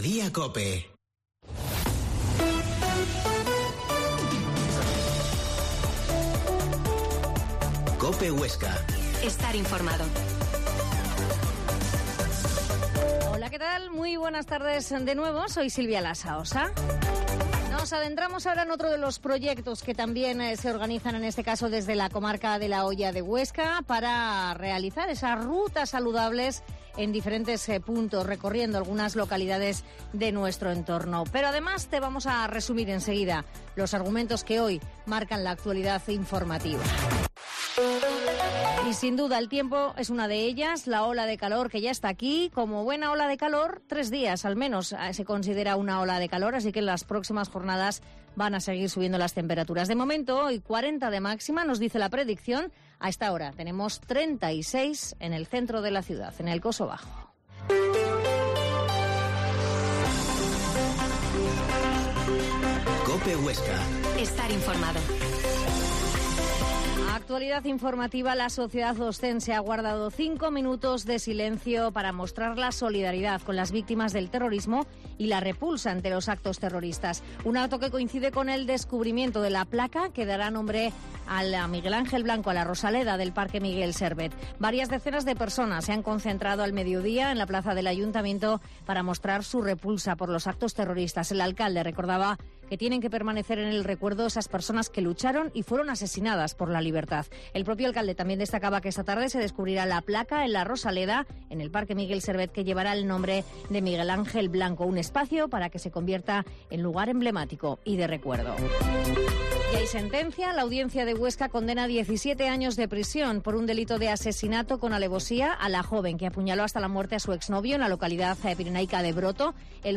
Mediodía en COPE Huesca 13.50h Entrevista a Sonia Blanco, Consejera de Medio Ambiente de la Comarca de Huesca